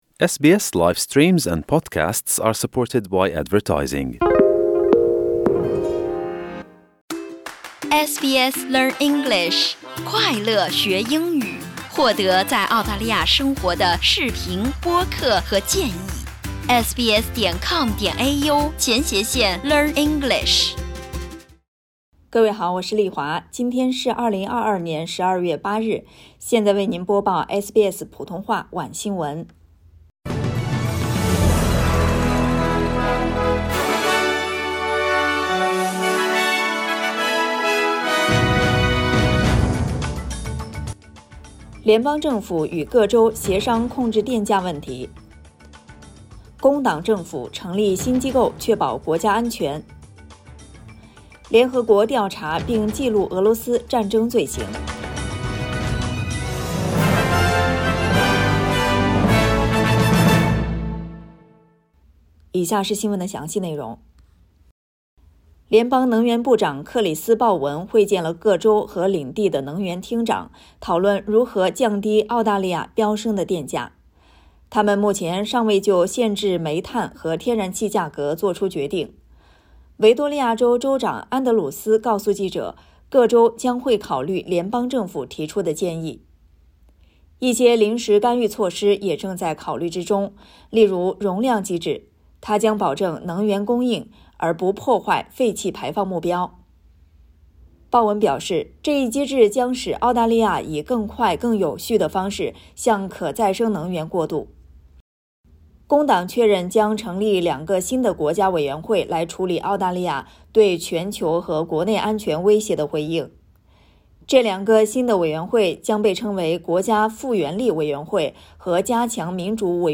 SBS晚新闻（2022年12月8日）